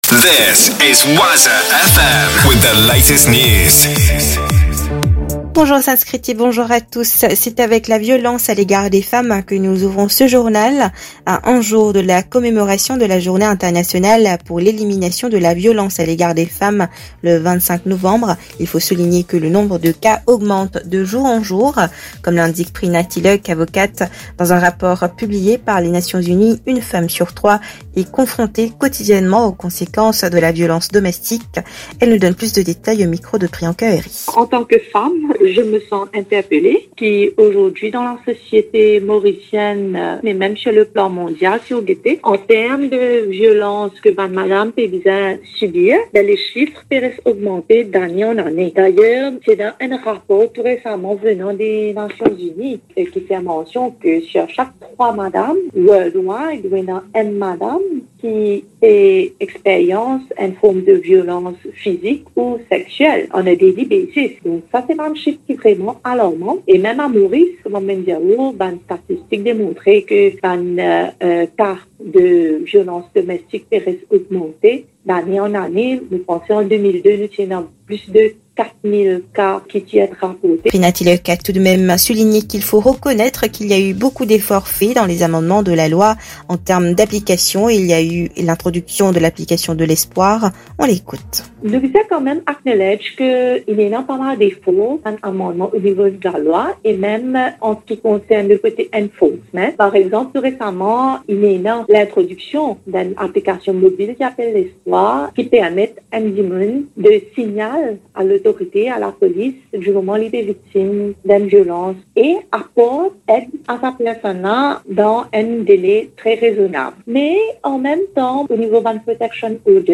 NEWS 8H - 24.11.23